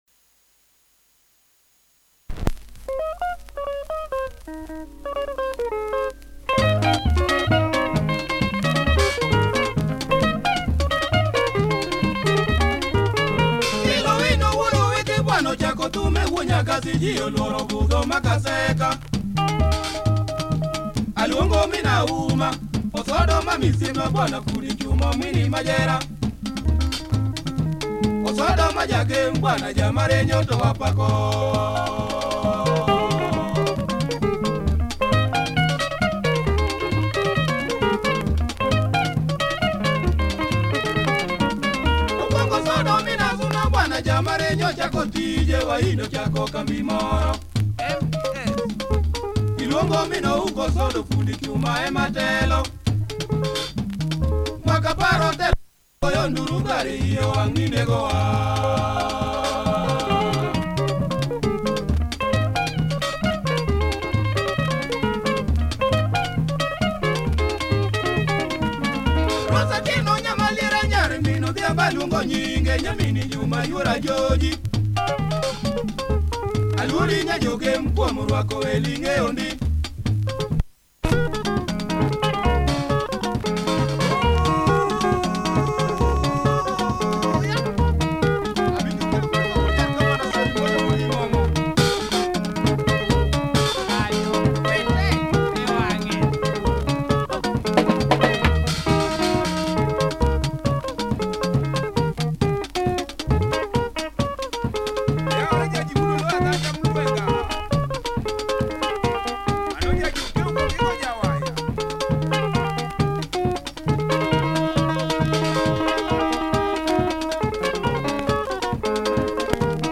Nice Luo benga